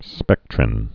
(spĕktrĭn)